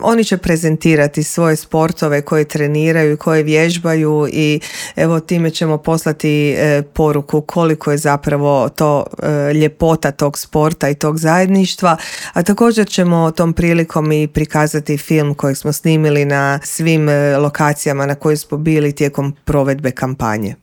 Više o samoj kampanji u intervjuu Media servisa